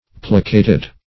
Search Result for " plicated" : The Collaborative International Dictionary of English v.0.48: Plicate \Pli"cate\, Plicated \Pli"ca*ted\, a. [L. plicatus, p. p. of plicare to fold.]